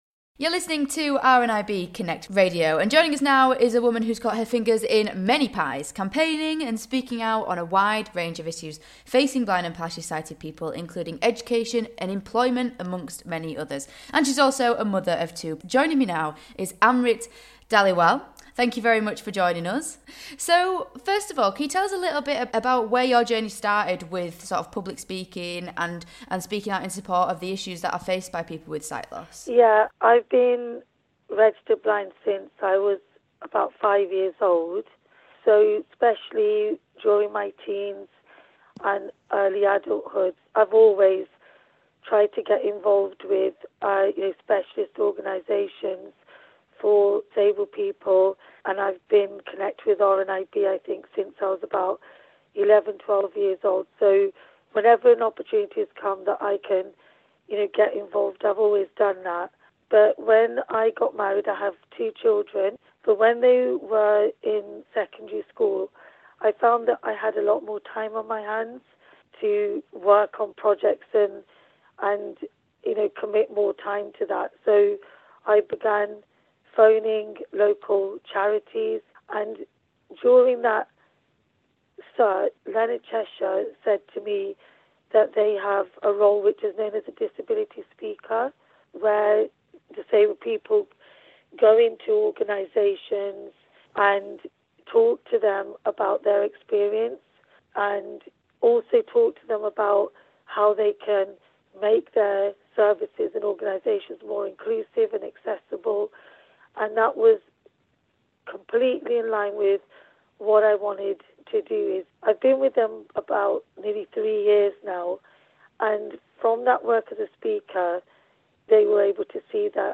She discusses all this and more in her conversation